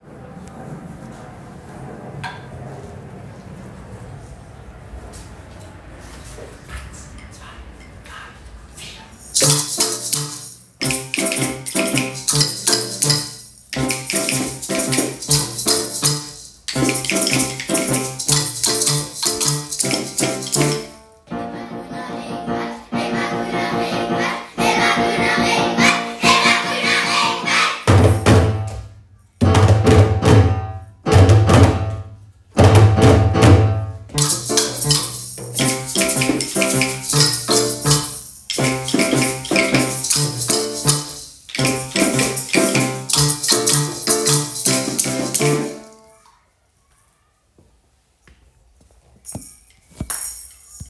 studieren die Kinder ein Lied ein. Es heißt: „Ich will nach Südamerika“ und nimmt die jungen Musikerinnen und Musiker mit auf eine musikalische Reise über den Atlantik.